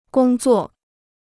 工作 (gōng zuò): to work; (of a machine) to operate.